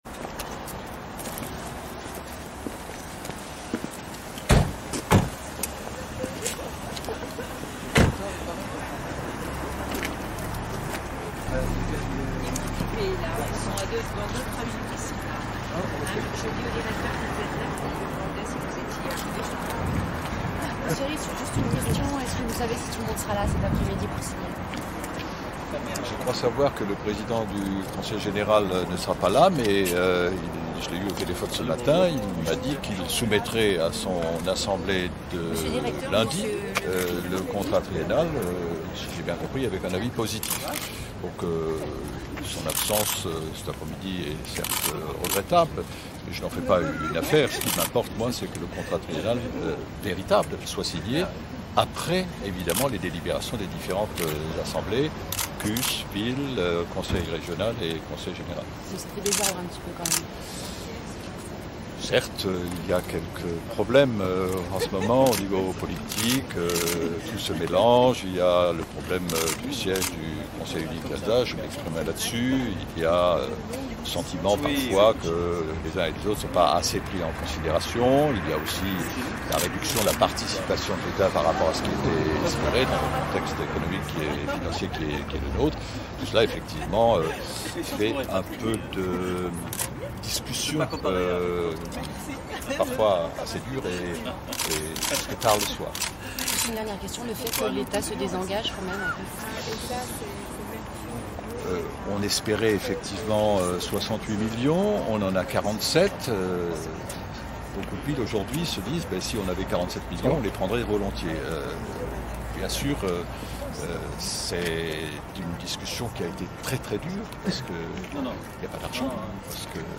interviewe